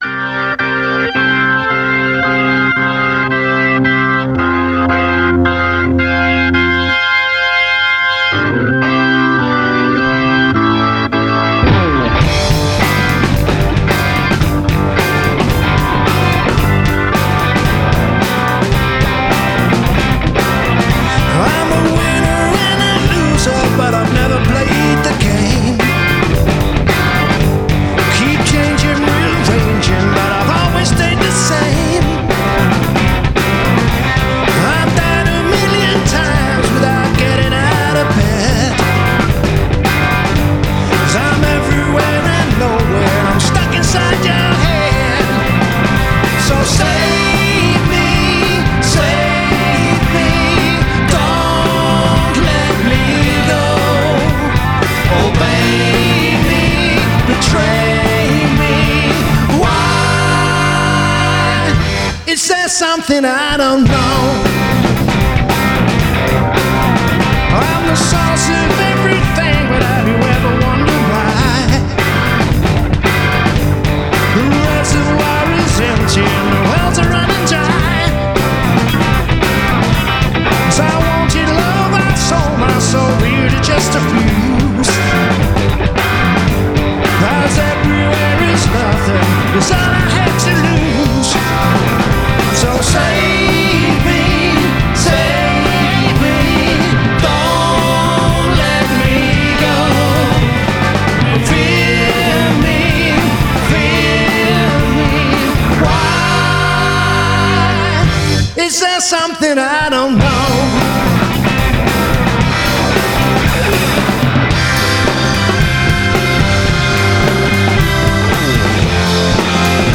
Vocal and Guitar
Vox Continental and Hammond Organ
Bass
Drums